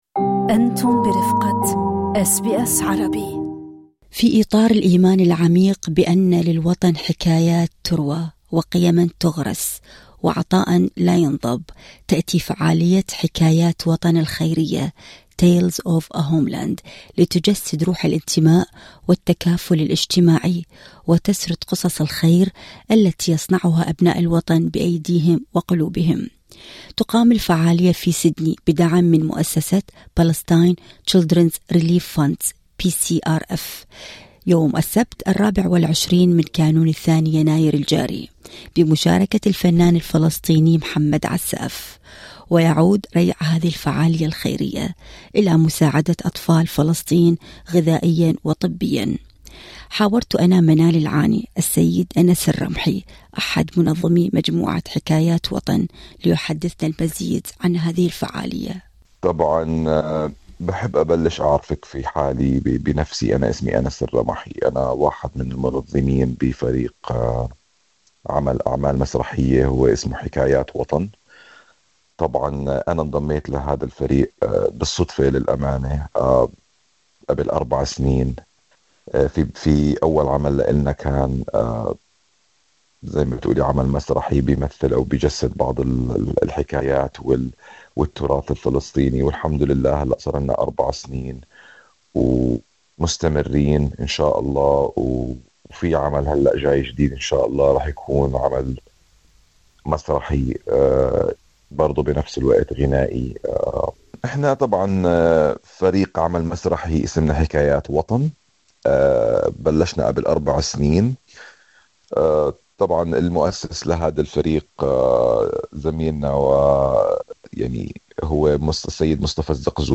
المزيد في لقاء